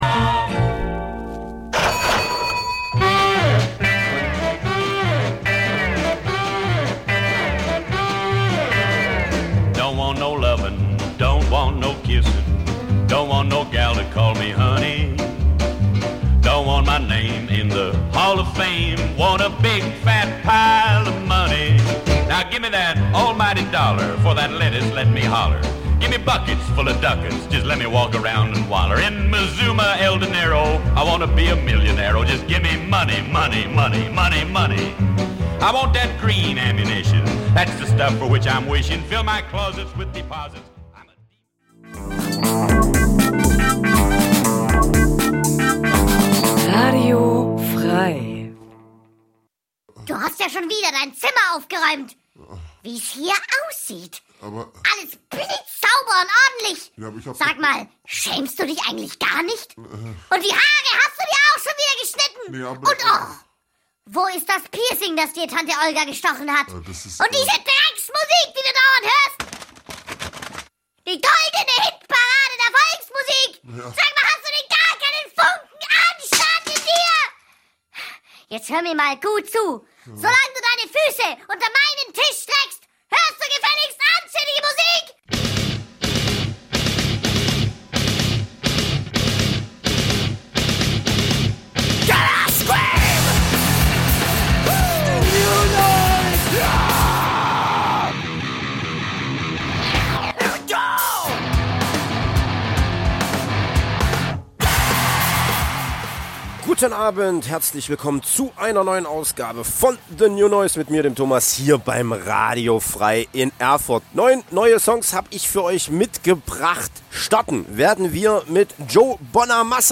...25 Jahre of total Krach... die beste Mischung aus genialen Neuvorstellungen und unerl�sslichen Konzerttips... aus dem old school, Metal-, Rock 'n' Roll-, Grind- und Hardcore- und sonstigen "gute Musik"- Bereichen...